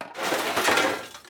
electronic_assembly_empty.ogg